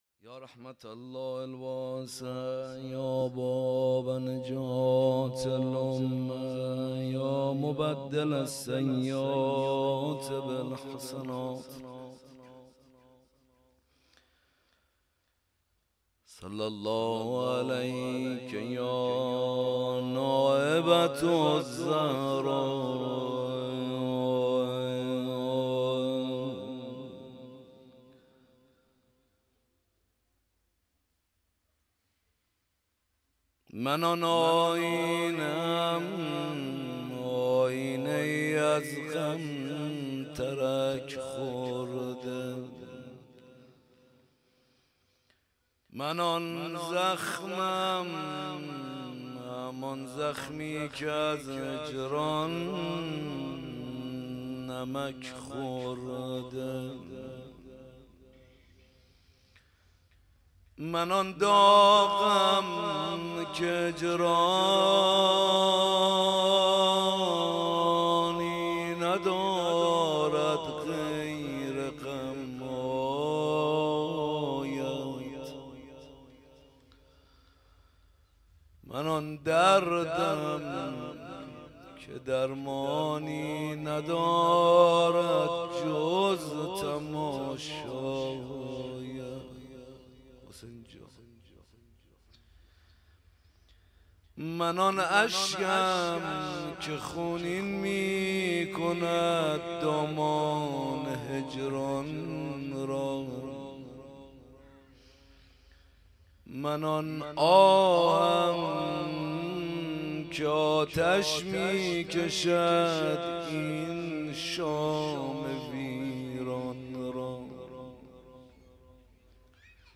خیمه گاه - حسینیه کربلا - روضه حضرت زینب سلام الله علیها
حسینیه کربلا